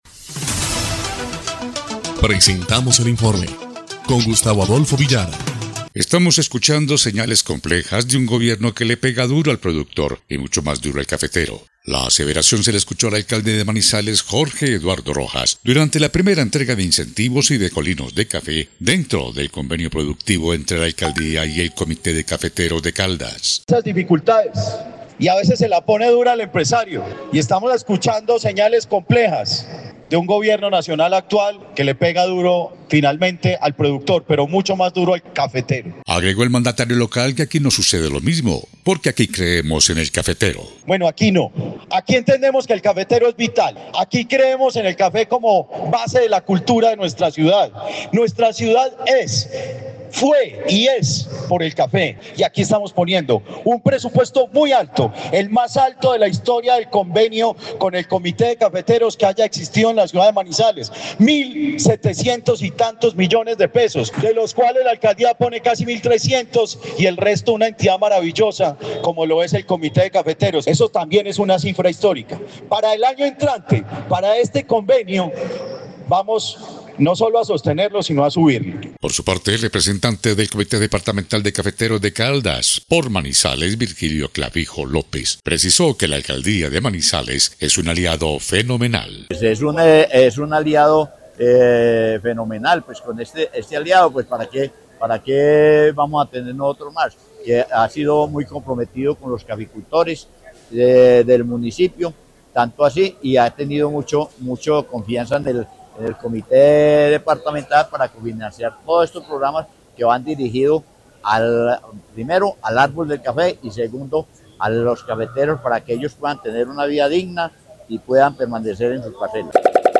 EL INFORME 3° Clip de Noticias del 11 de julio de 2025